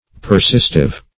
Persistive \Per*sist"ive\, a.